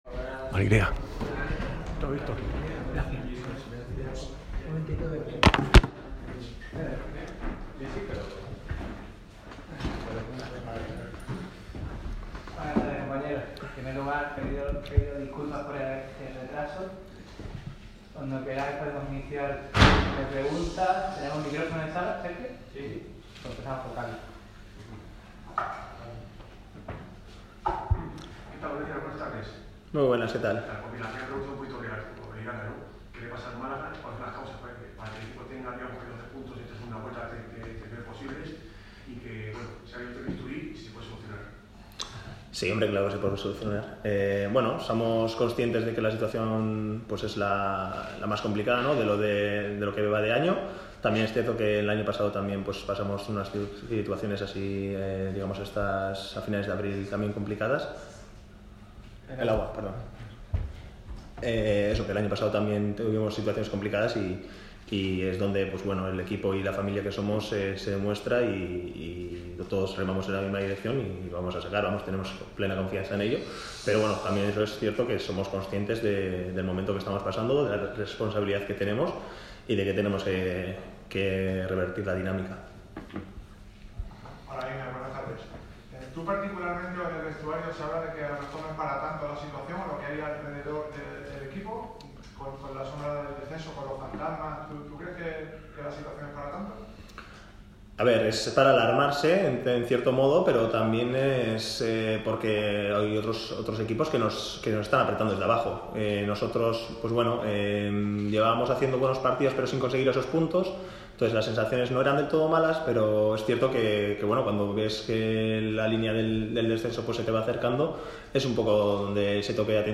Por ello, uno de los protagonistas de la zaga blanquiazul como Einar Galilea ha comparecido este miércoles ante los medios.